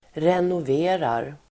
Uttal: [renov'e:rar]